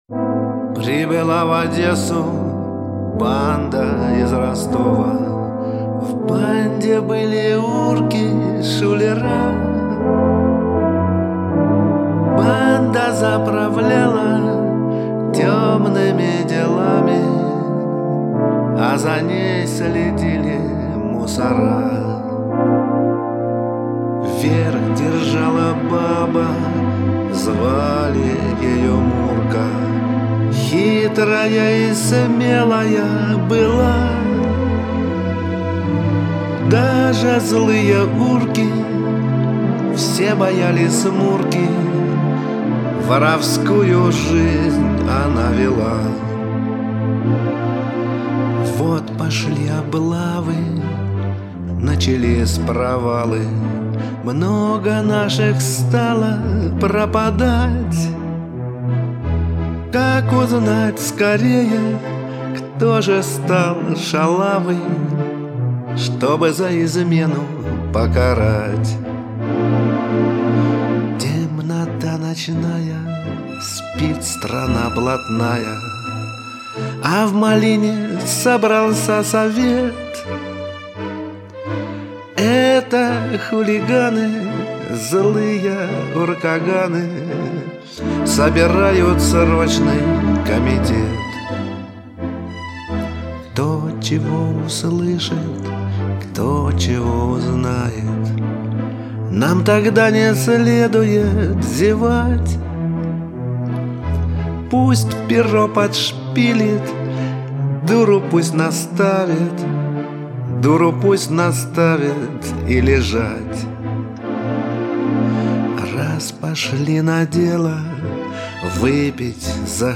Мой вступительный взнос: "Мурка" в исполнении "Козла на саксе" в сопровождении симфонического оркестра